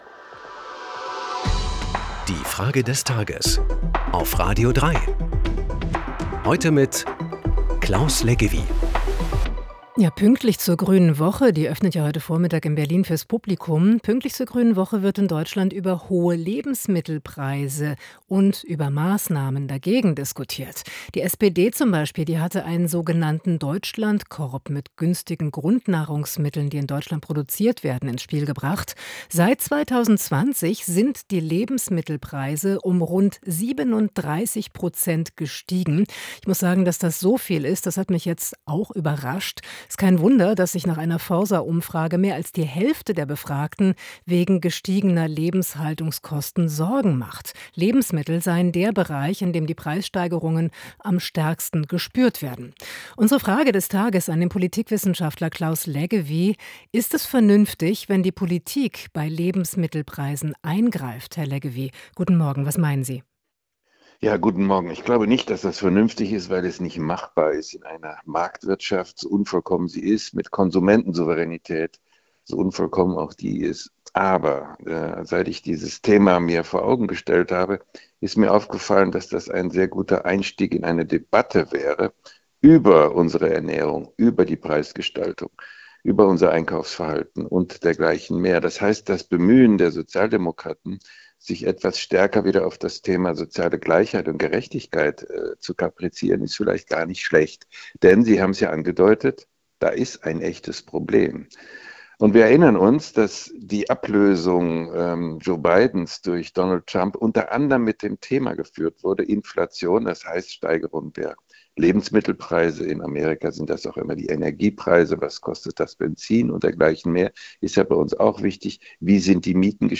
Wir fragen den Politikwissenschaftler Claus Leggewie in der Frage